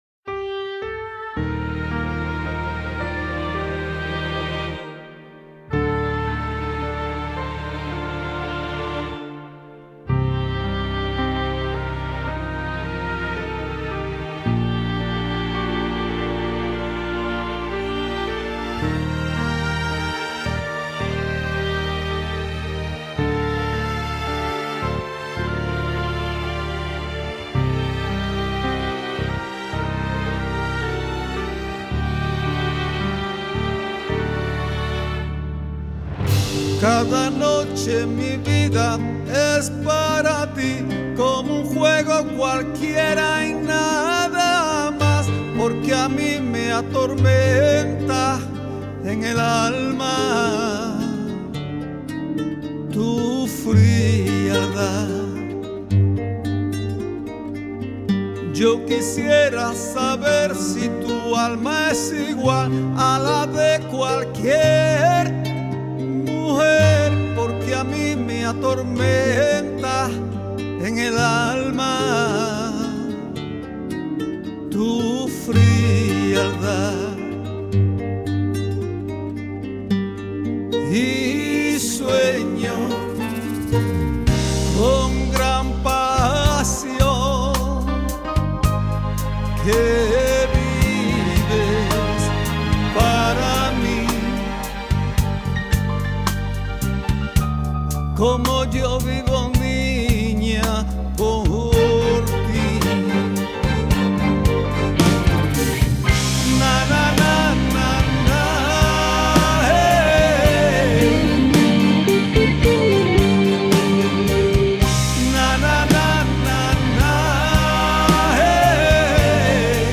La banda de rock andaluza